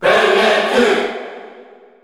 Category: Crowd cheers (SSBU) You cannot overwrite this file.
Byleth_Male_Cheer_Korean_SSBU.ogg